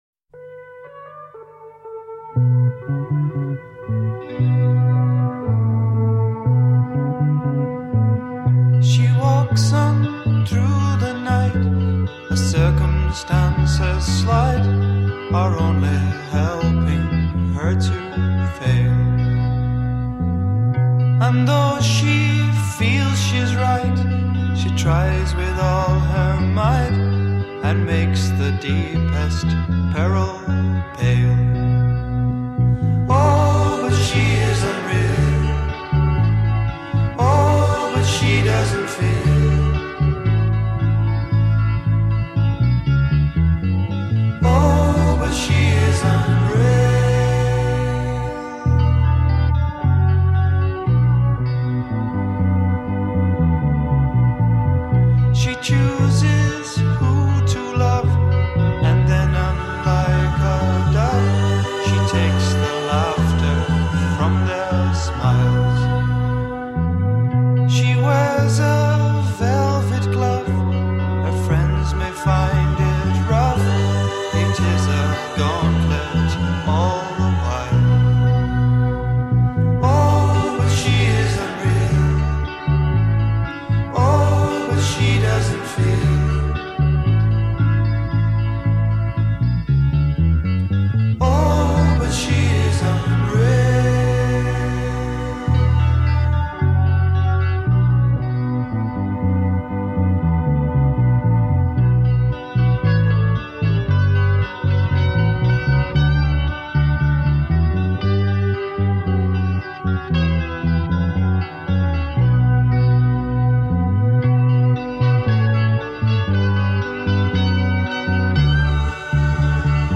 prog rock